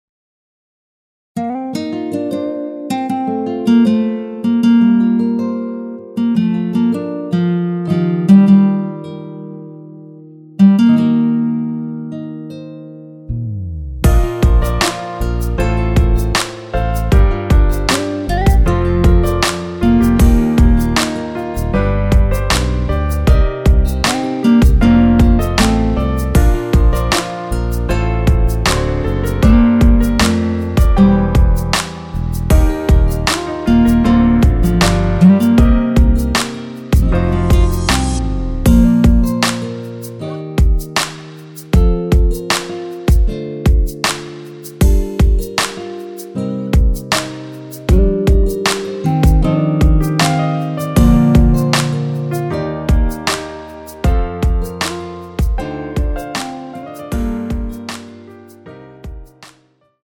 원키에서(-6)내린 남성분이 부르실수 있는키의 MR입니다.
Bb
앞부분30초, 뒷부분30초씩 편집해서 올려 드리고 있습니다.
중간에 음이 끈어지고 다시 나오는 이유는